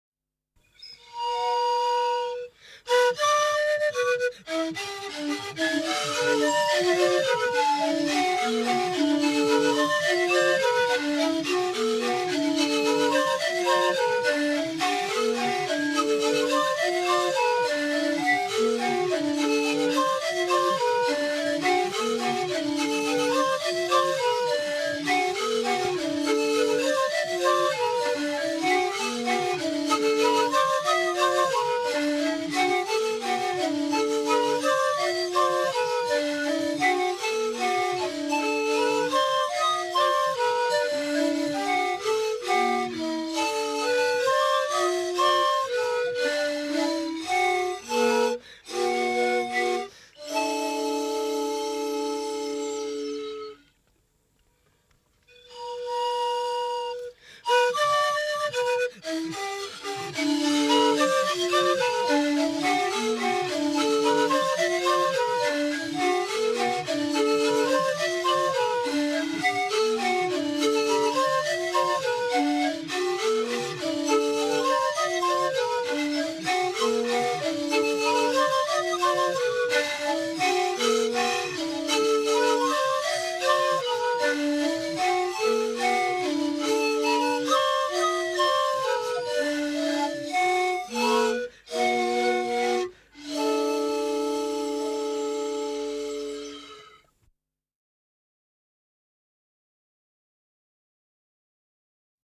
Ensemble de 6 flûtes de
L’échelle employée est à nouveau la gamme équi-heptaphonique, mais dans le cas de l’ensemble ’au Keto, chaque flûte ne comporte pas tous les degrés de cette gamme.
Six musiciens en cercle se faisant face interprètent un répertoire polyphonique à trois voix, chacune d’elles étant doublée à l’octave.